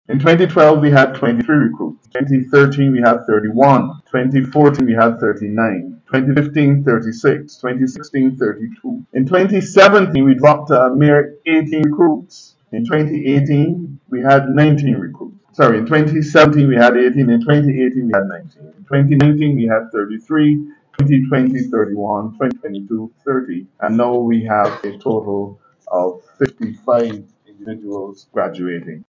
The 147th Passing-Out Parade for police recruits was held at the Regional Police training centre, yesterday.
Attorney General Dale Marshall says this is the largest number of recruits in over 10 years.